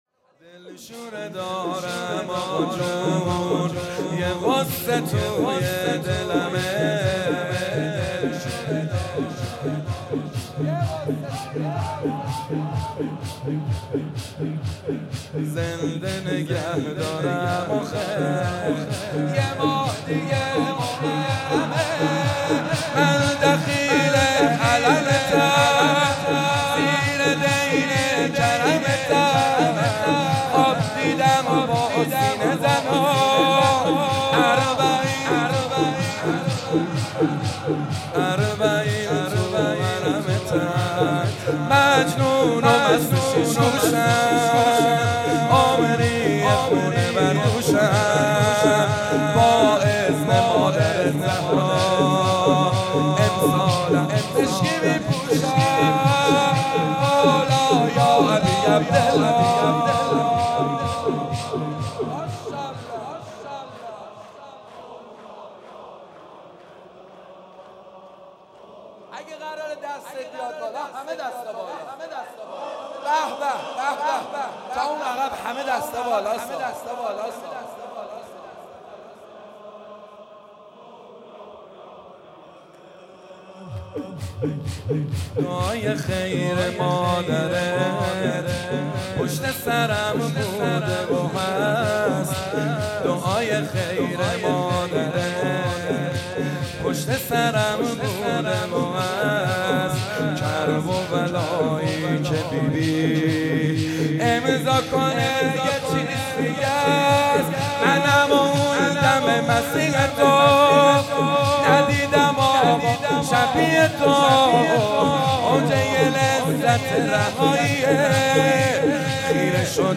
(روضه)
(شور جدید)
(مناجات)